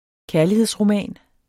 Udtale [ ˈkæɐ̯liheðs- ]